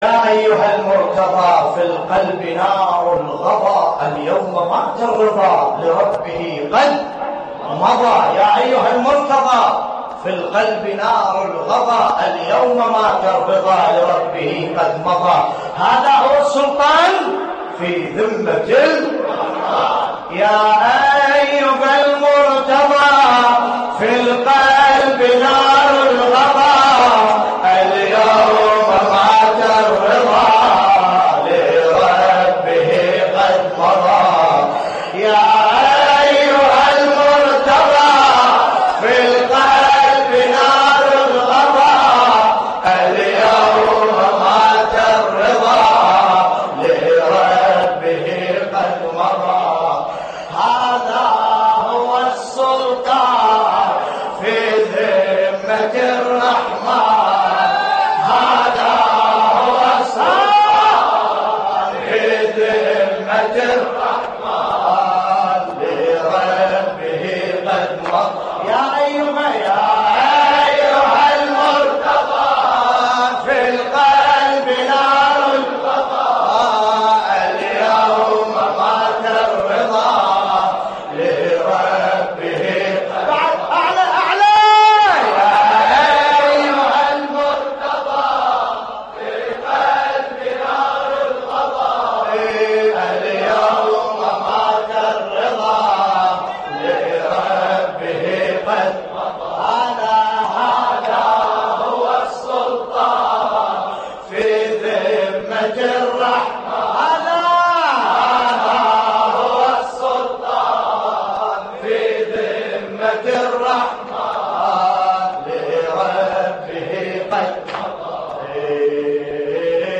تحميل : يا أيها المرتضى في القلب نار الغضى اليوم مات الرضا / الرادود باسم الكربلائي / اللطميات الحسينية / موقع يا حسين